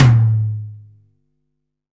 Index of /90_sSampleCDs/300 Drum Machines/Akai MPC-500/1. Kits/Fusion Kit
F Stand Tom 2.WAV